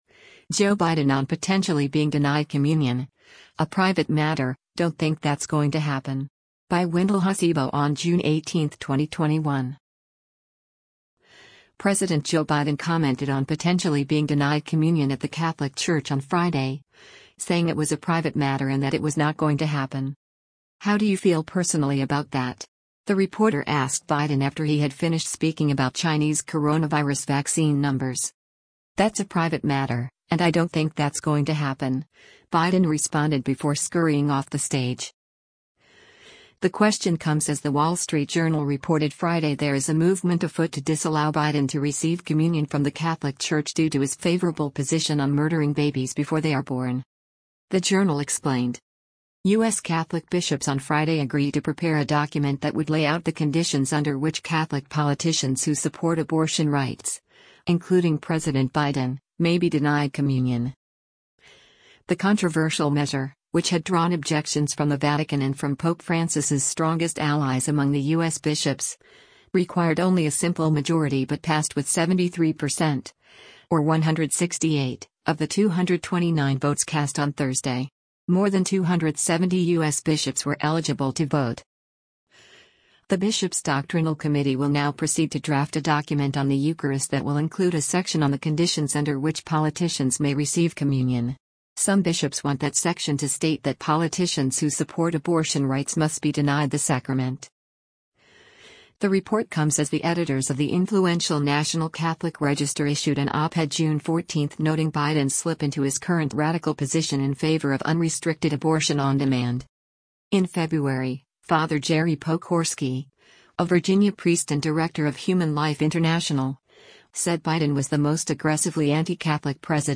“How do you feel personally about that?” the reporter asked Biden after he had finished speaking about Chinese coronavirus vaccine numbers.